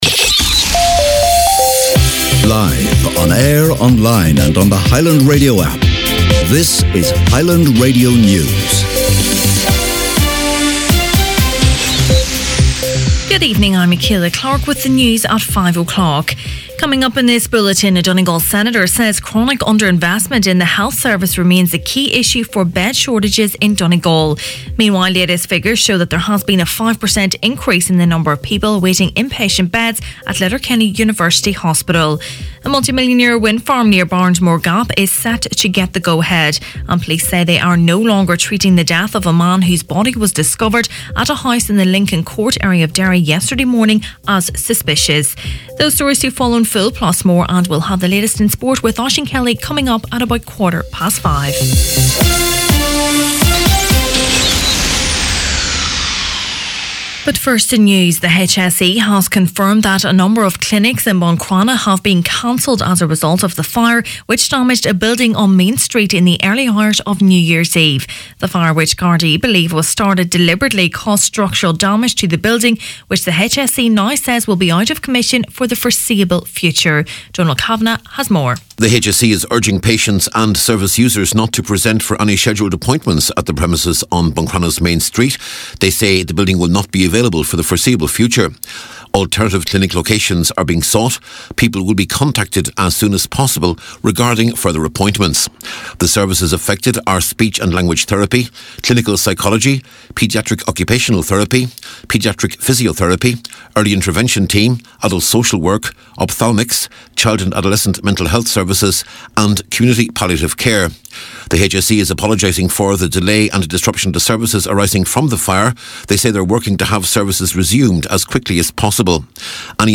Main Evening News, Sport and Obituaries Wednesday January 2nd